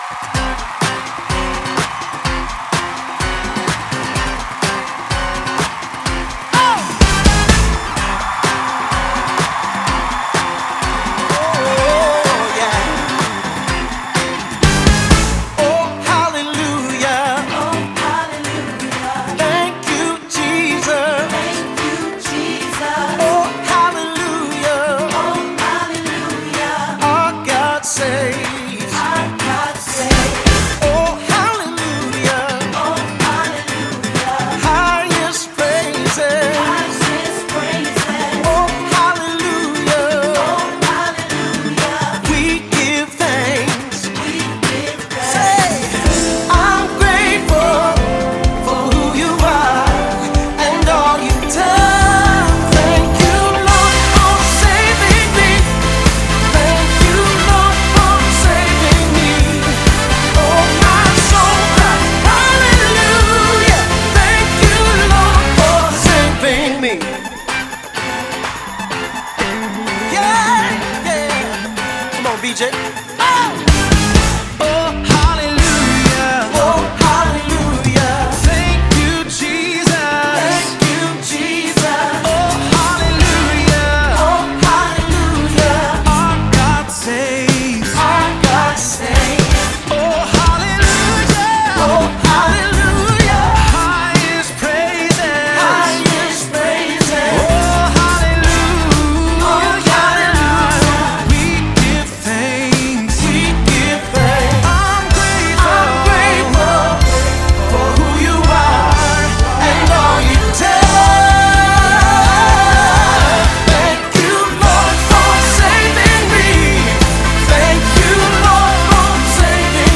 1543 просмотра 703 прослушивания 87 скачиваний BPM: 126